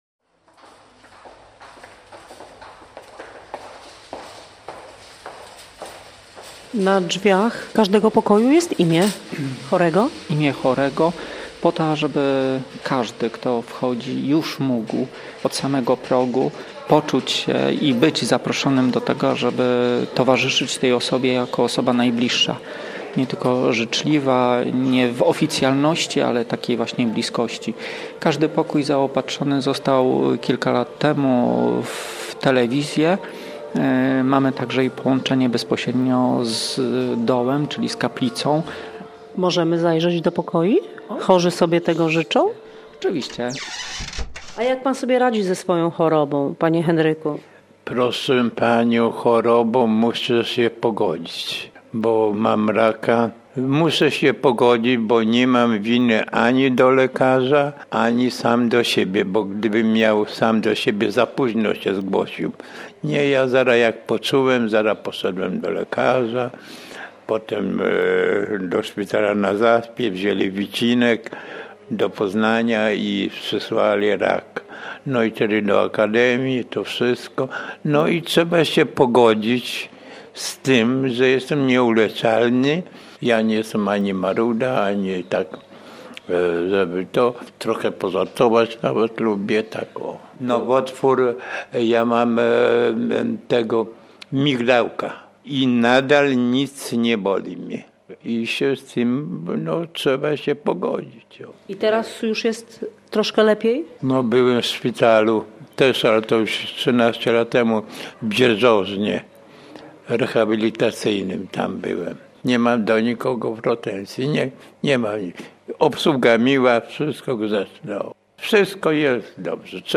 Takie słowa usłyszała dziennikarka Radia Gdańsk wchodząc do sopockiego hospicjum.
reportaż